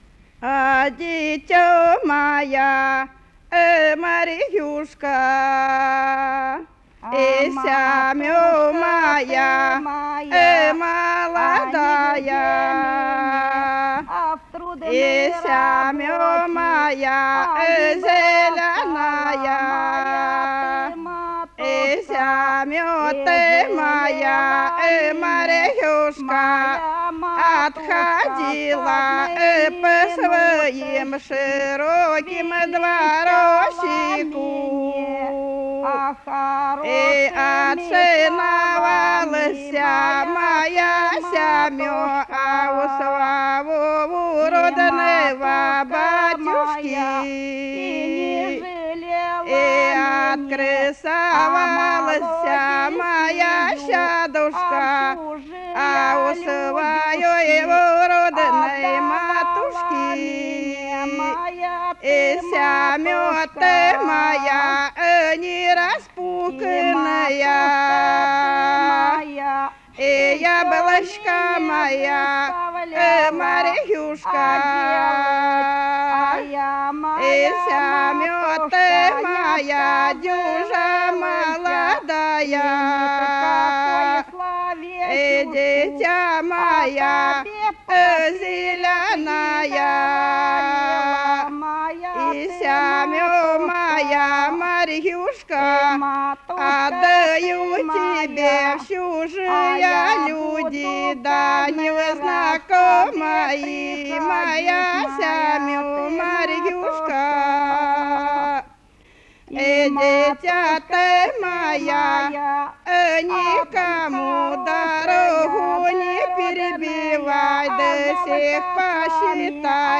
Свадебное голошение невестиной матери «А дитё моя»